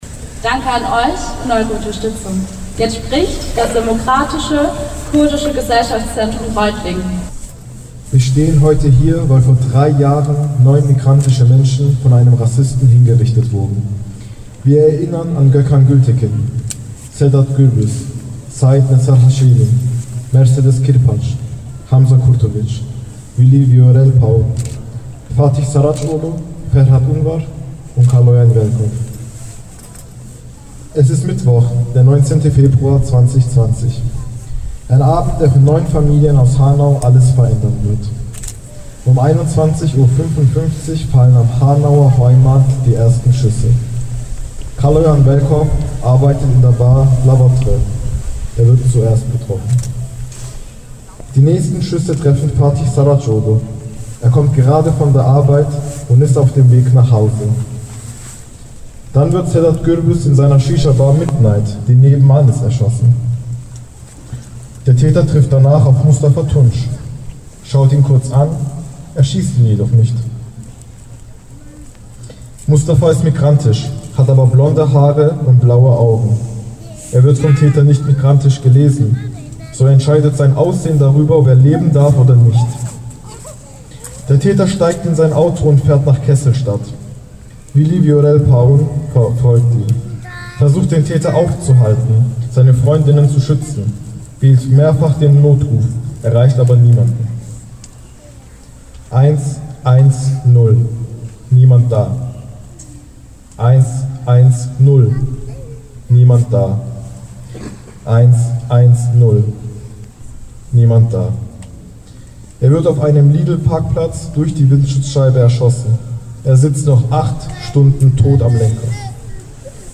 "Hanau ist überall": Demo in Tübingen
"3 Jahre Hanau - niemals vergessen!" Unter diesem Motto fand zum dritten Jahrestag der rassistischen Morde in Hanau eine Demo in Tübingen statt, zu der geschätzt mehrere hunderte Menschen kamen.
Zweite Rede
90257_Hanau-Demo.mp3